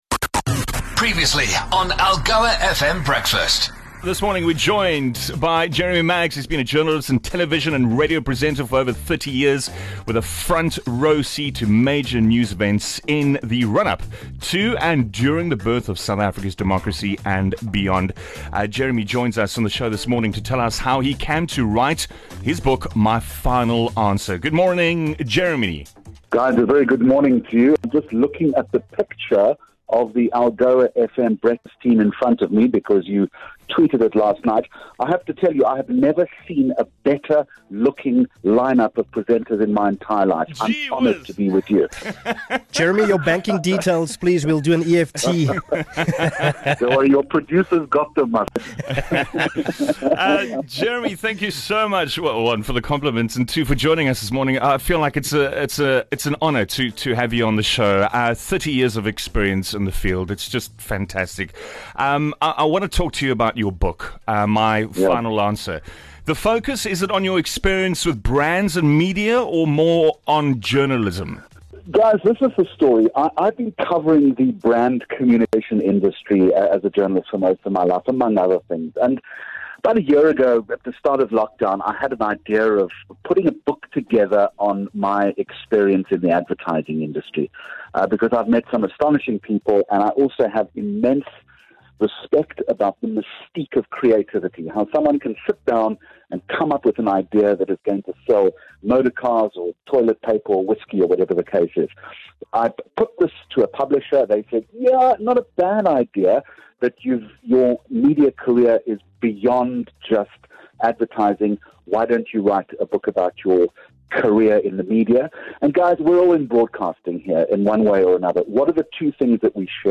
Called 'My Final Answer ' - it's bound to be a best-seller - Maggs on the Breakfast Show!